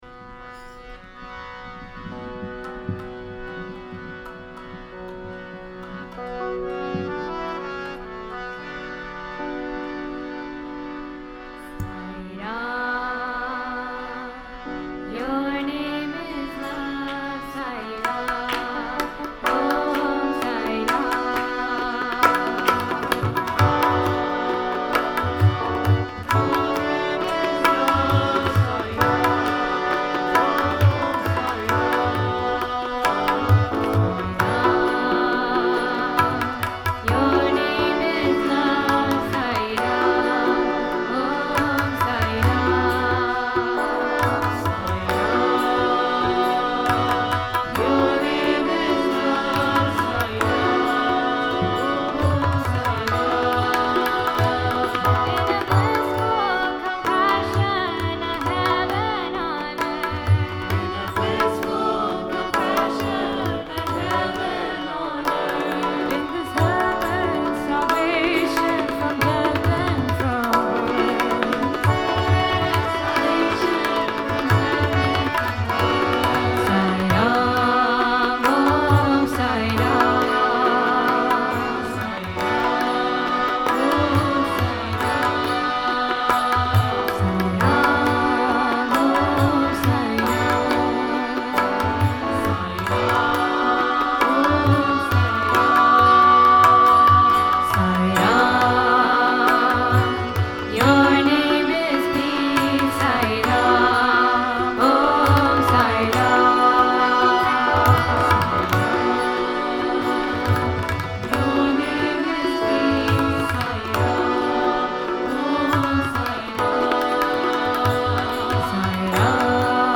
1. Devotional Songs
Minor (Natabhairavi)
Harmonic Minor
6 Beat / Dadra
Slow